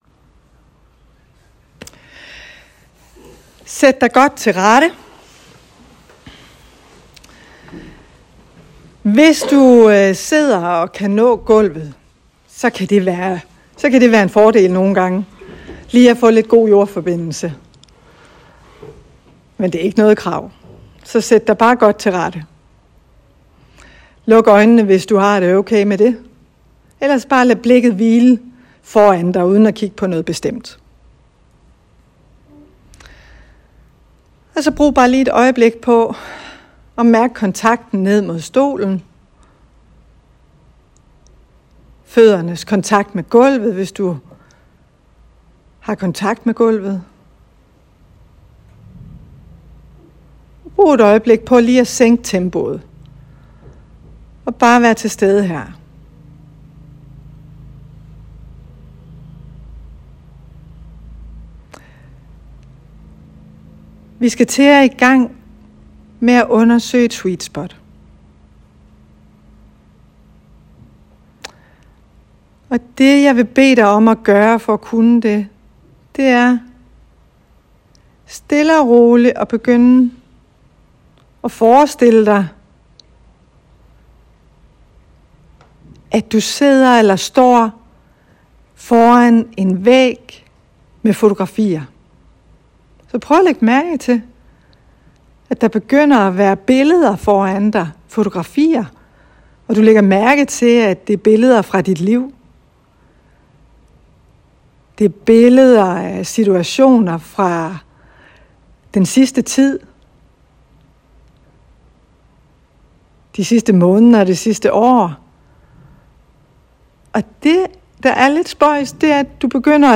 Temadag for sundhedsprofessionelle i Region Nordjylland om brugen af ACT-matrixen i arbejdet med kroniske smertepatienter.
Lydfilen blev optaget på min mobiltelefon undervejs på kurset.